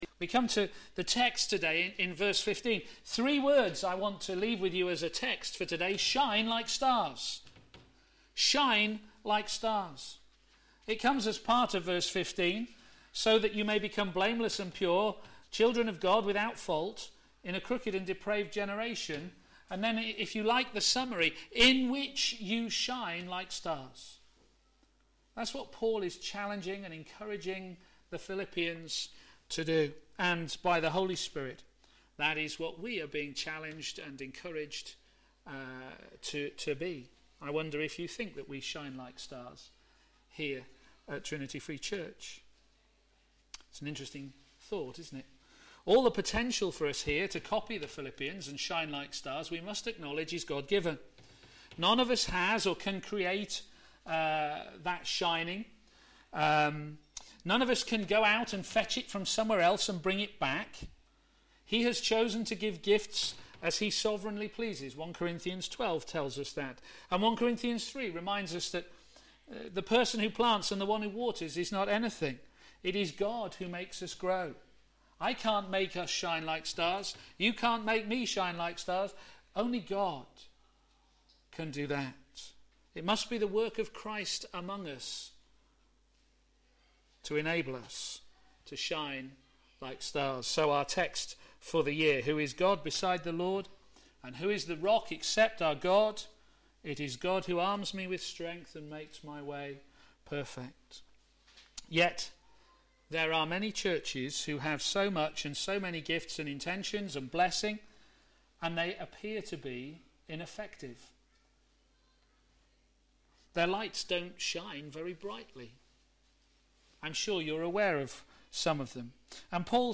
a.m. Service
Sermon